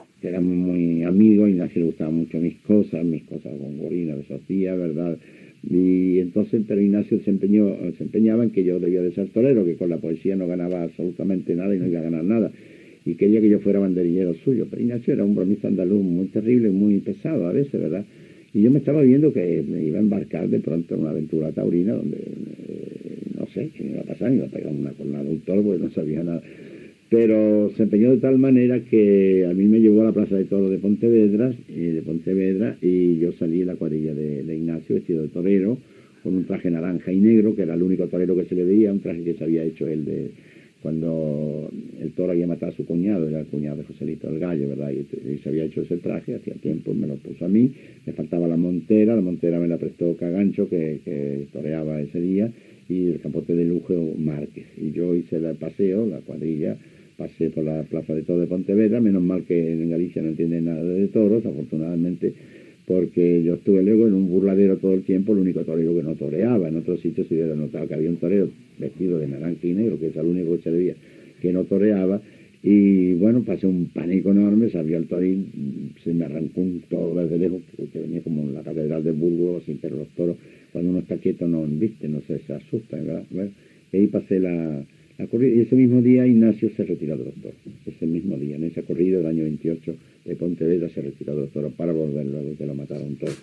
El poeta Rafael Alberti recorda els seus inicis i el primer premi que va rebre.